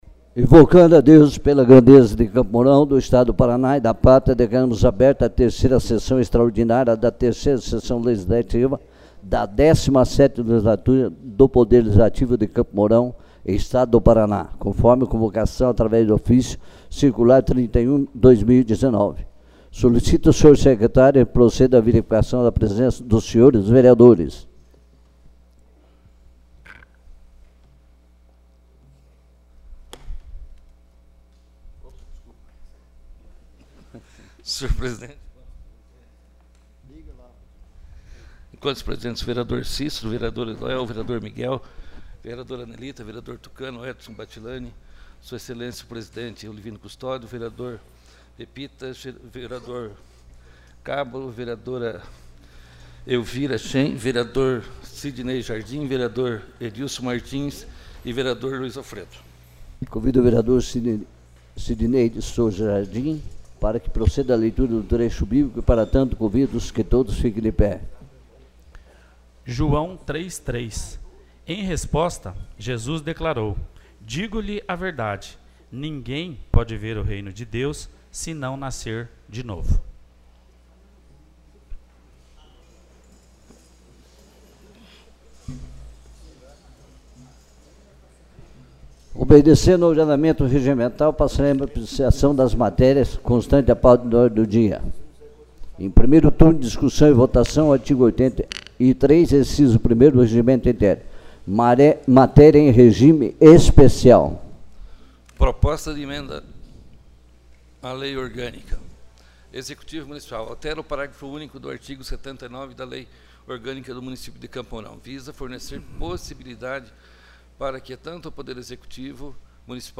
3ª Sessão Extraordinária